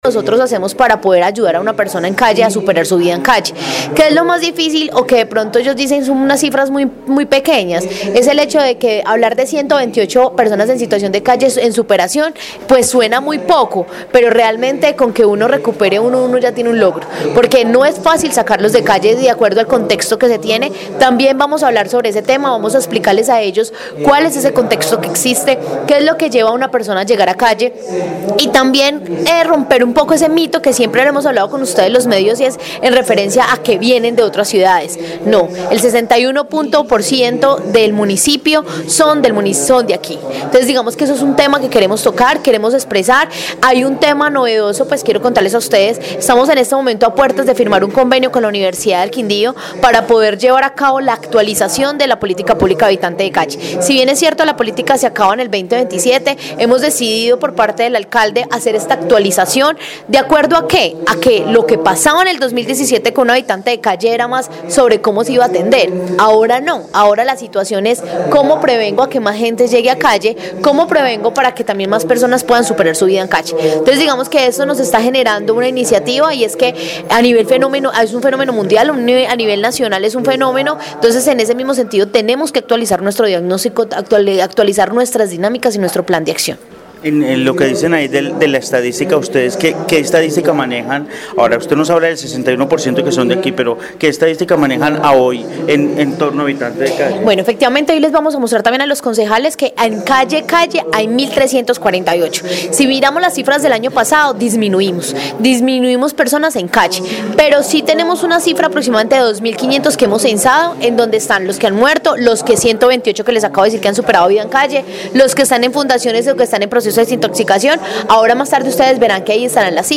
Jenny Gómez, secretaria de desarrollo social de Armenia